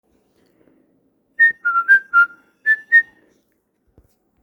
Melodia z programu/reklamy
Chodzi mi po głowie melodia ale nie wiem skąd ją znam może ktoś pomoże rozpoznać( gwizdane), każdy mówi że zna ale nie wie skąd :D